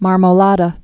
(märmō-lädə, -dä)